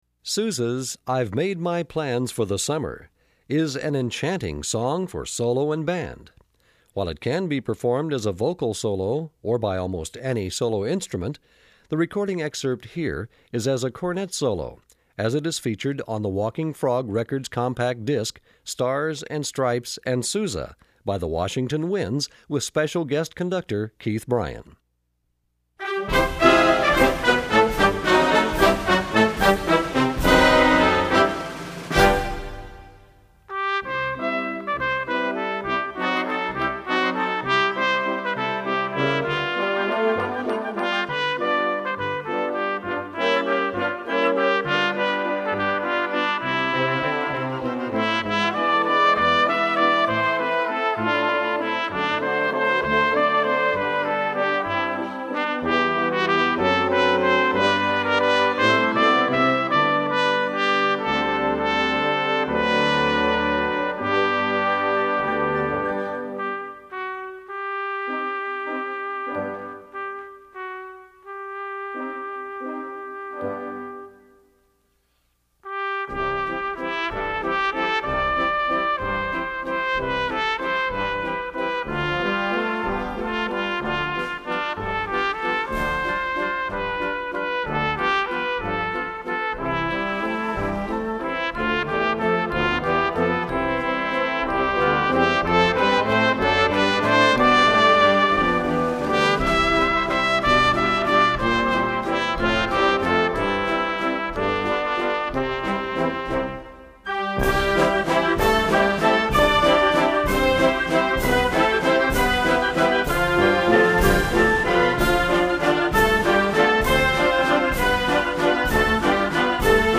Besetzung: Blasorchester
True Americana!